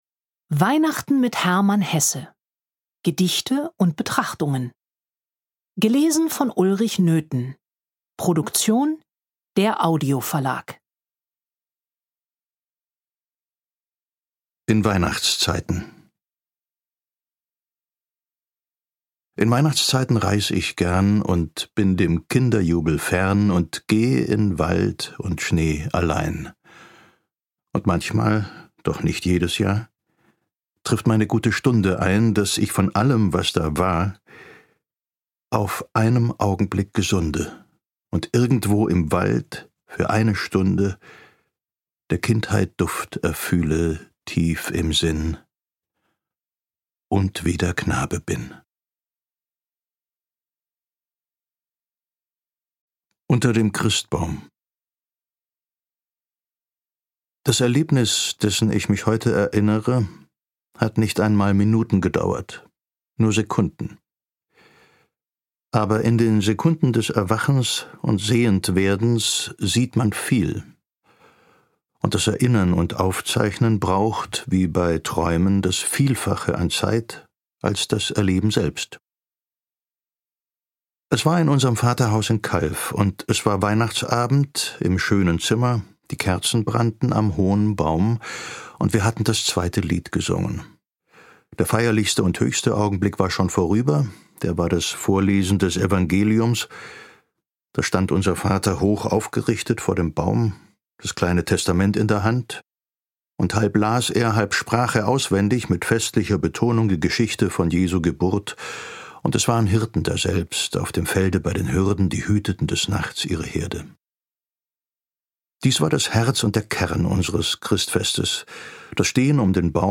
Lesung mit Ulrich Noethen (1 CD)
Ulrich Noethen (Sprecher)
Dennoch findet er immer wieder Halt und Schönheit in winterlichen Landschaften, aufmerksamen Geschenken und nicht zuletzt in seinem Glauben – einfühlsam interpretiert von Ulrich Noethen.